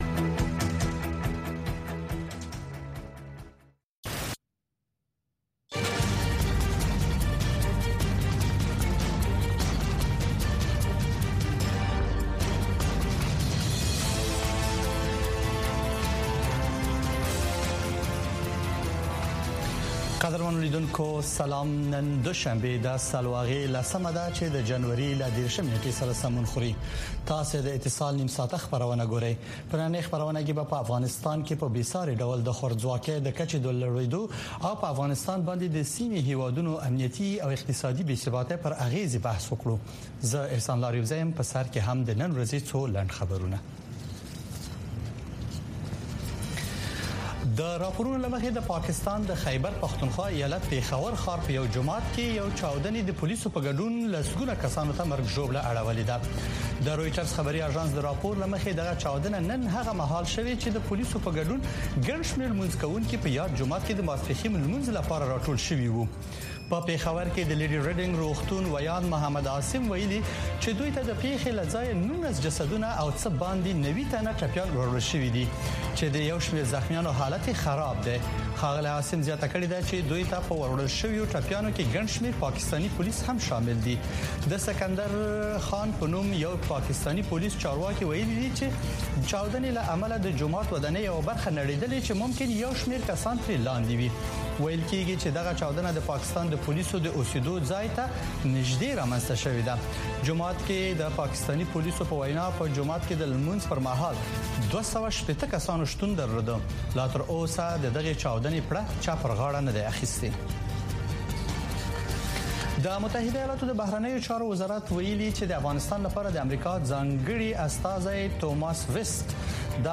په دې خپرونه کې د چارواکو، شنونکو او خلکو سره، مهمې کورنۍ او نړیوالې سیاسي، اقتصادي او ټولنیزې مسئلې څېړل کېږي. دغه نیم ساعته خپرونه له یکشنبې تر پنجشنبې، هر مازدیګر د کابل پر شپږنیمې بجې، په ژوندۍ بڼه خپرېږي.